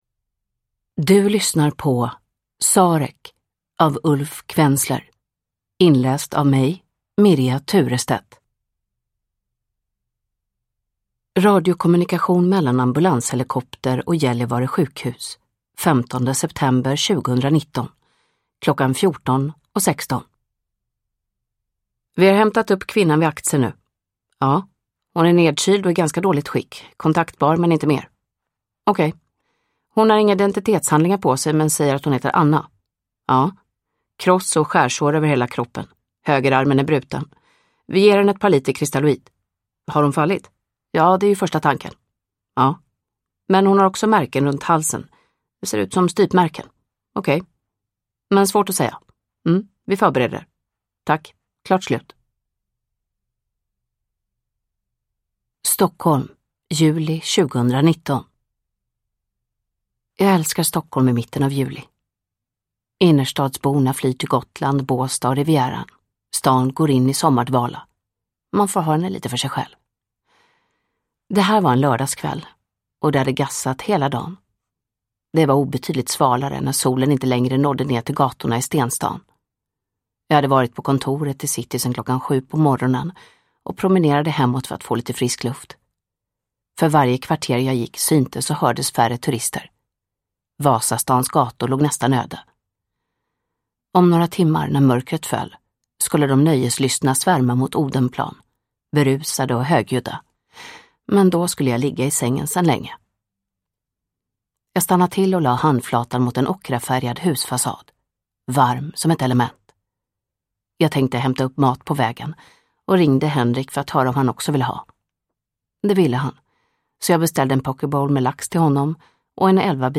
Sarek – Ljudbok – Laddas ner
Uppläsare: Mirja Turestedt